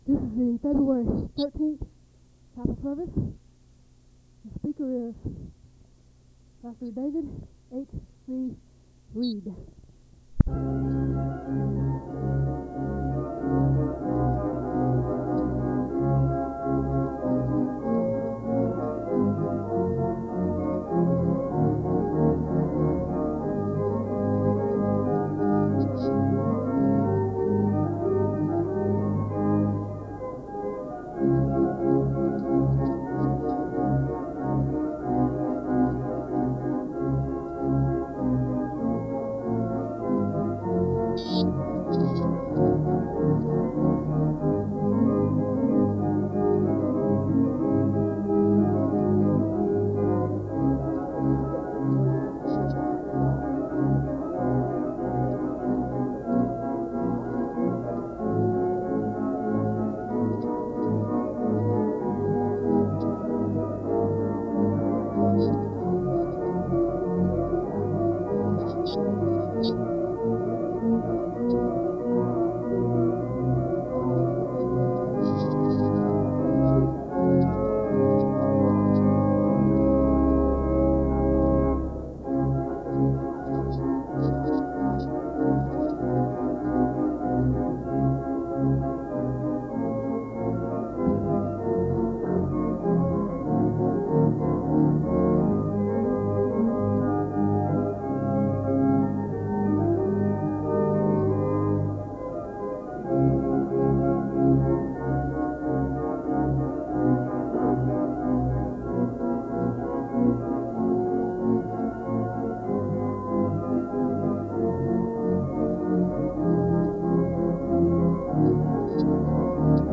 The service begins with organ music and a moment of prayer (0:00-3:35).
The choir sings the anthem (6:26-8:56).
The service ends in a word of prayer (51:11-51:27).
SEBTS Chapel and Special Event Recordings SEBTS Chapel and Special Event Recordings